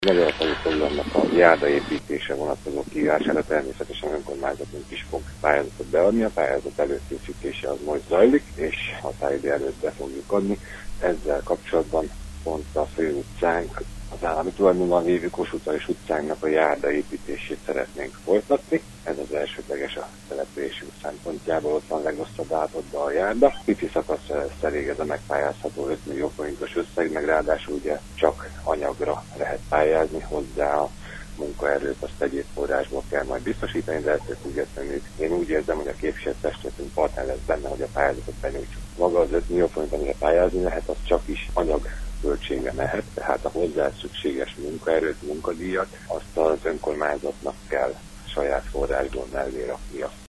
Nagy János polgármestert hallják: